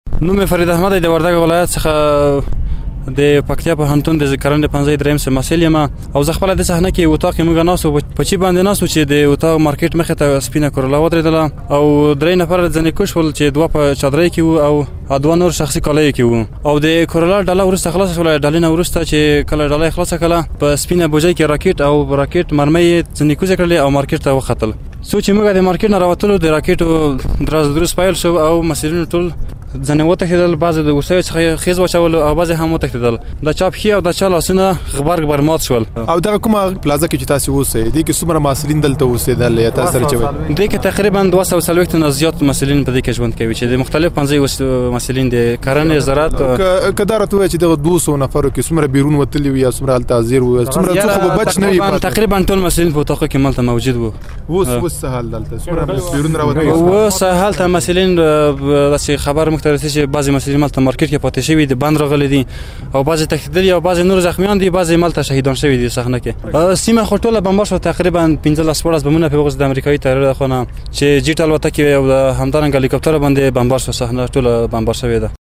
د پکتيا له يوه محصل سره مرکه